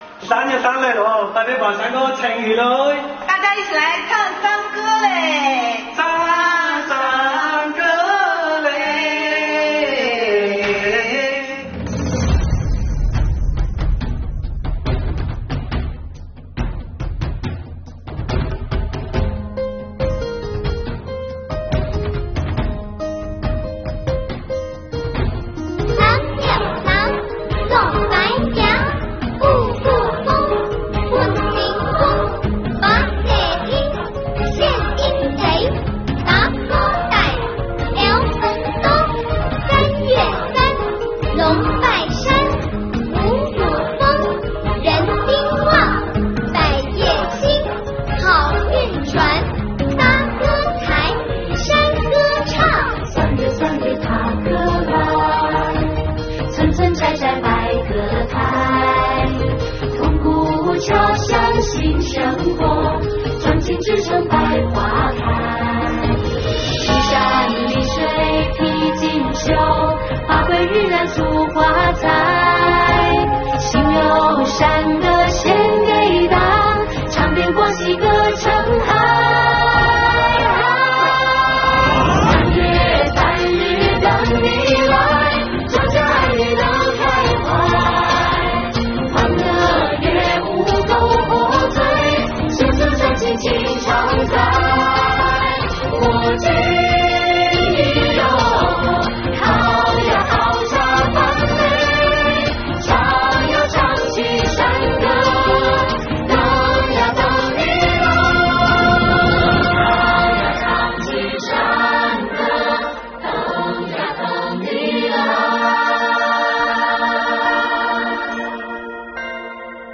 “壮族三月三”来了，快把山歌唱起来！
大家一起来唱山歌咧~